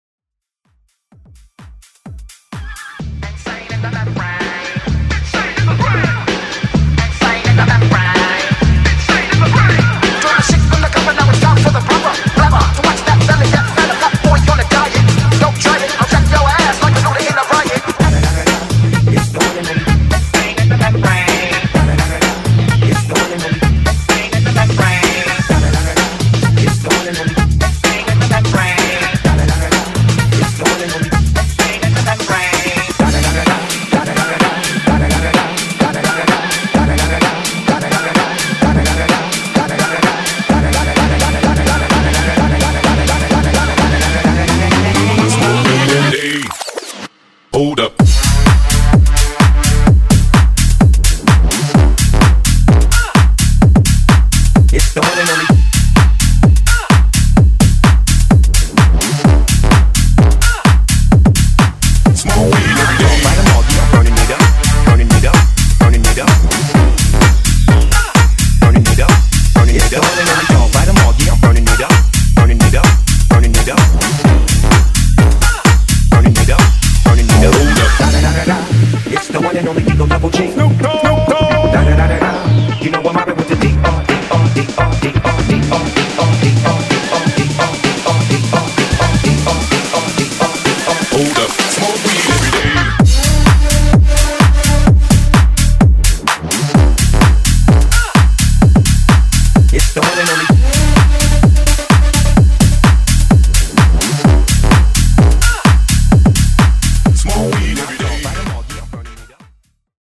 Genre: EDM
Clean BPM: 130 Time